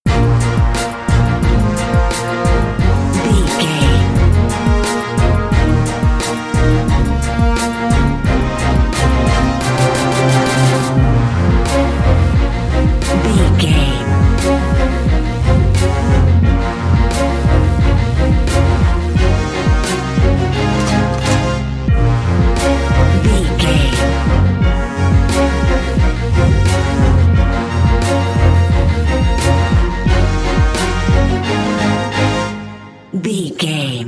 Fast paced
In-crescendo
Aeolian/Minor
B♭
strings
drums
horns
hip hop
soul
Funk
neo soul
confident
energetic
bouncy
funky